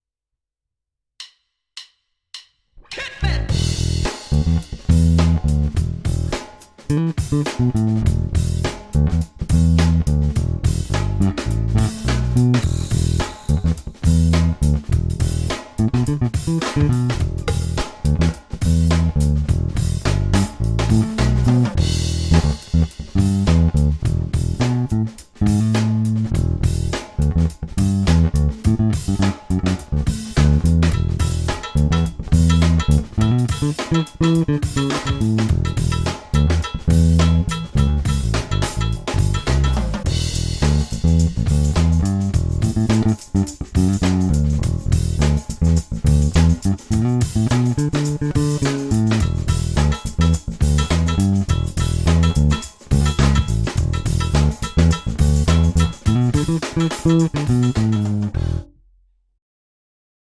PB Grooveek čistě do linky, bez úprav.